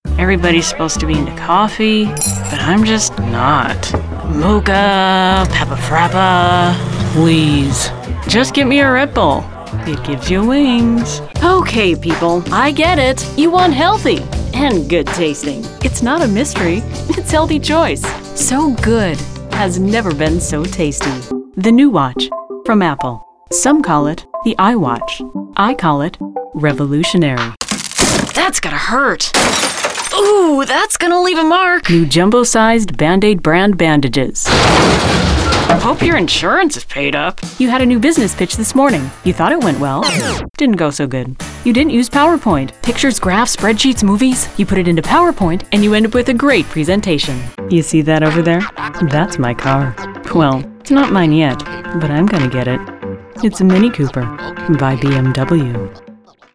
• 2444 | Inglés – EE.UU y Canadá
Comercial
2444-english_us_and_canada-female-commercial.mp3